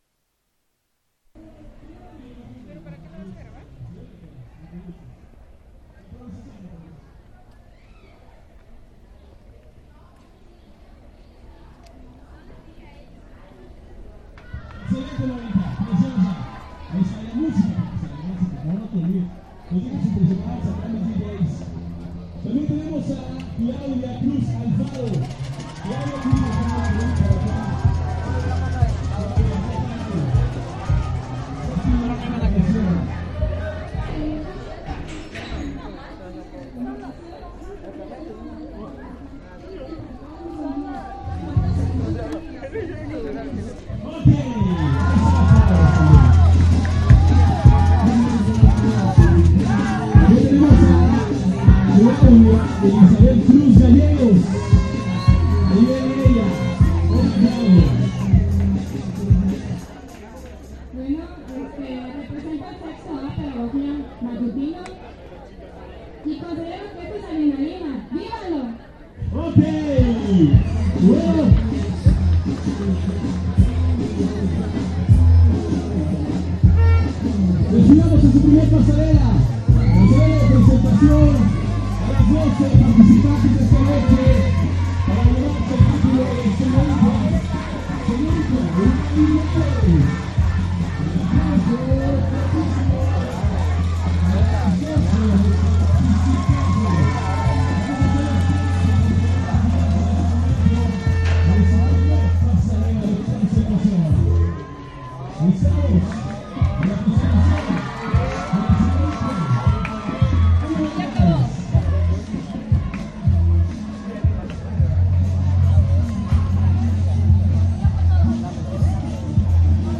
En la Universidad Autónoma de Chiapas, en la Facultad de Humanidades se realiza este evento estudiantil.